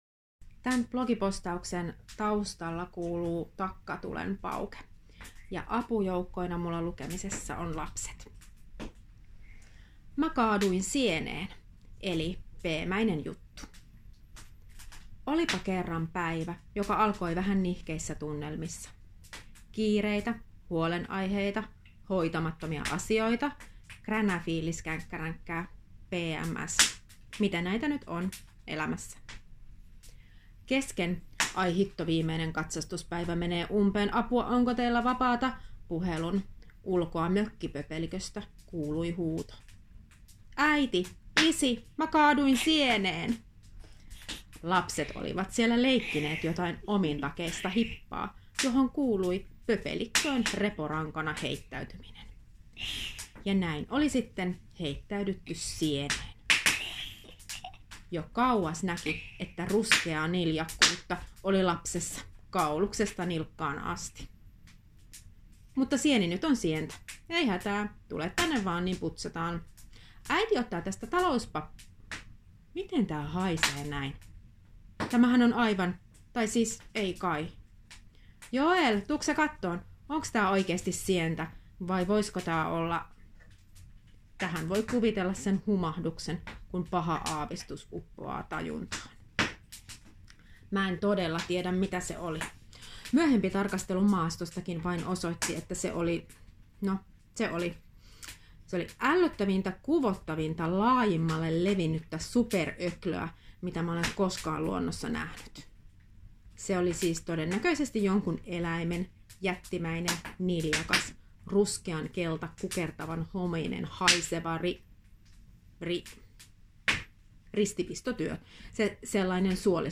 Tämä oli erittäin low effort, lapset roikkuivat kyljessä ja takkatuli paukahteli, kerralla purkkiin vaan.
Sulla on mukavan kuuloinen ääni, ja toi takkatulen raksuna sai melkein haistamaan, ei sitä ristipistoa vaan ihanan mökkituoksun.
Tähän tekstiin sopi hyvin sadunomainen lukutyyli.